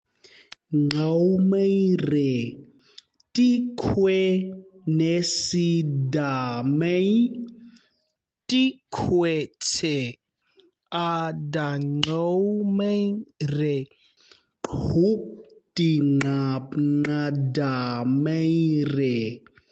Pronunciation Guide
nau_maire_-_pronunciation_guide.mp3